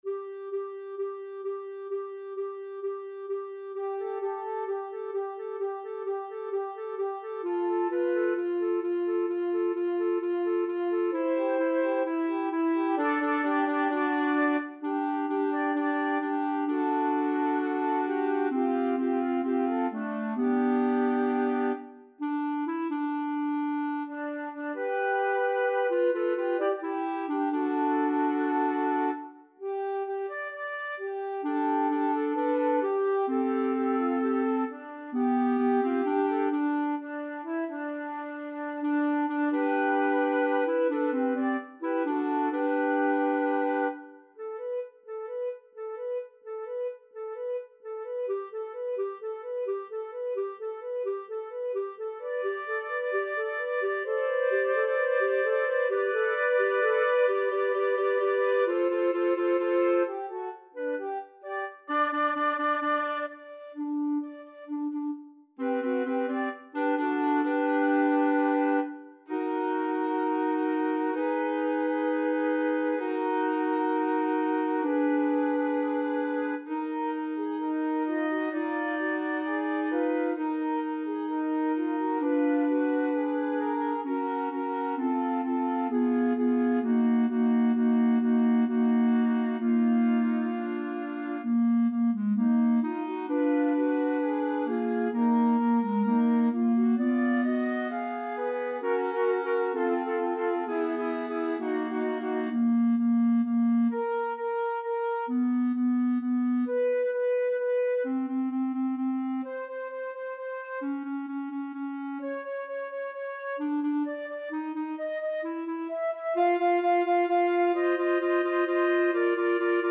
3-part female choir, SSA a capella
世俗音樂
本曲共有一序奏及四個段落。